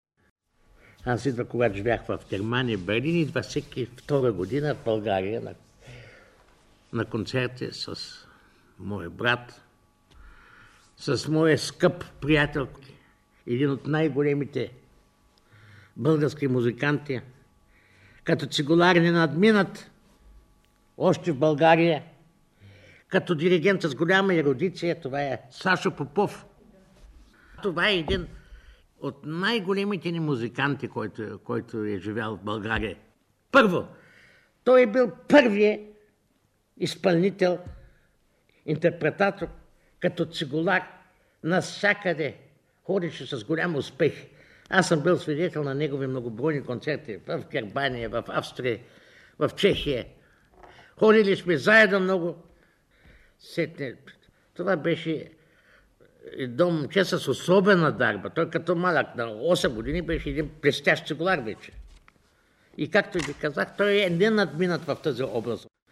За този период от неговия живот си спомня Панчо Владигеров в запис от 1977 година, запазен в Златния фонд на БНР: